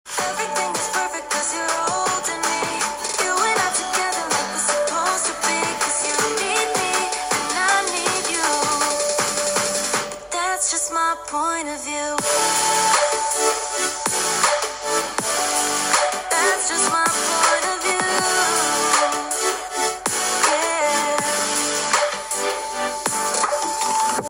Galaxy Z Fold4は、ステレオスピーカーを搭載。
肝心の音については、"良い"な印象です。
▼Galaxy Z Fold4のステレオスピーカーの音はこちら！